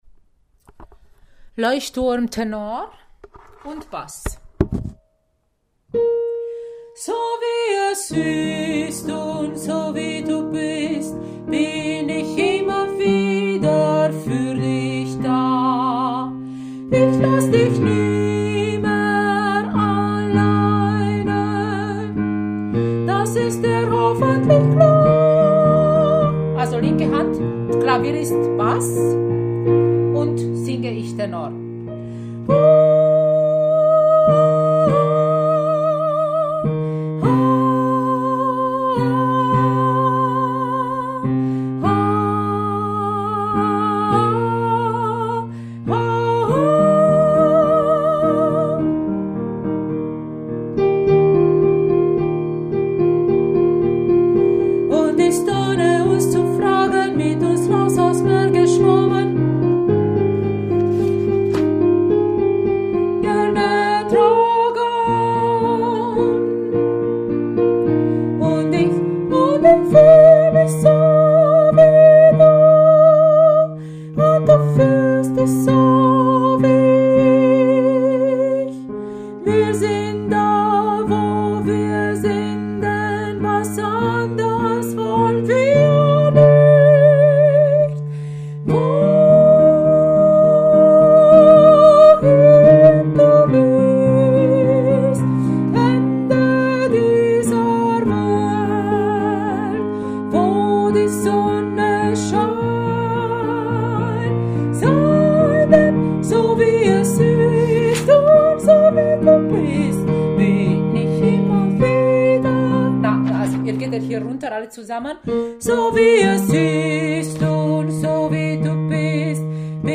Bass/Tenor